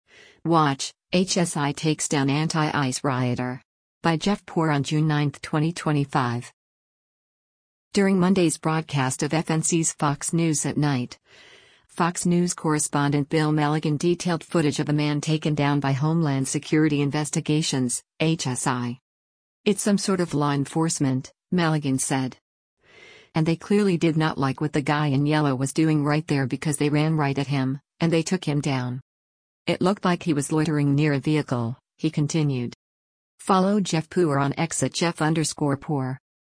During Monday’s broadcast of FNC’s “Fox News @ Night,” Fox News correspondent Bill Melugin detailed footage of a man taken down by Homeland Security Investigations (HSI).